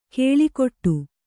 ♪ kēḷikoṭṭu